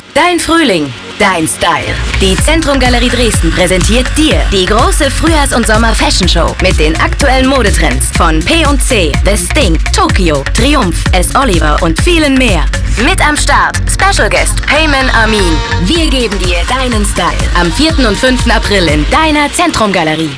Auswahl Radiowerbespots